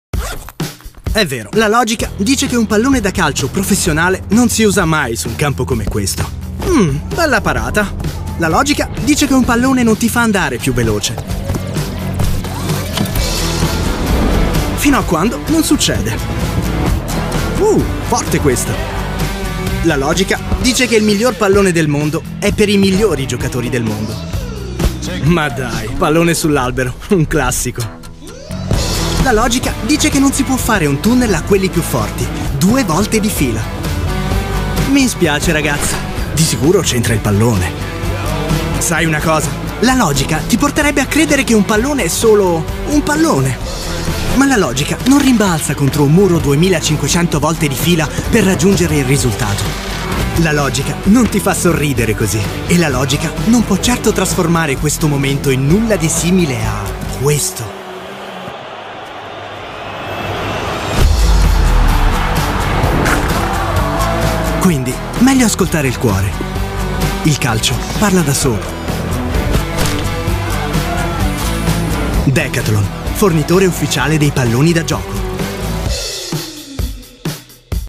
Italiaanse voice-over
Commercieel